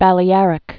(bălē-ărĭk)